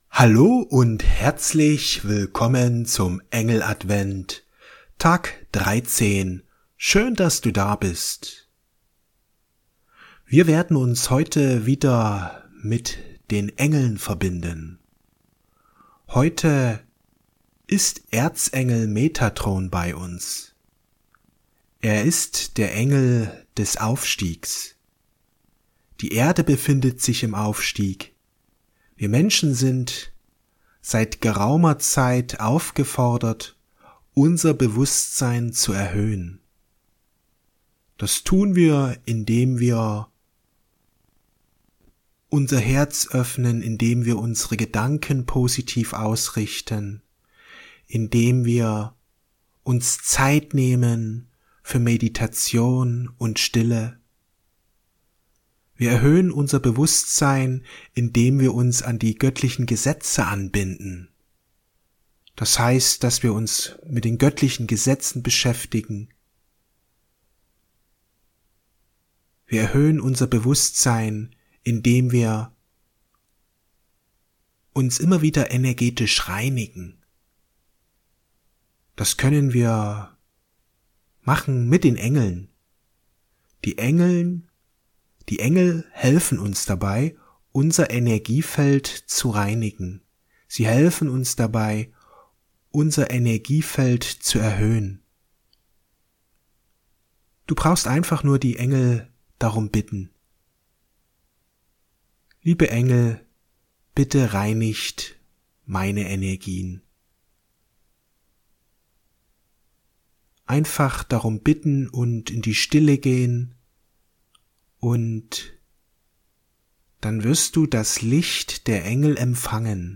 Der Aufstieg ins erhöhte Bewusstsein Meditation mit Erzengel Metatron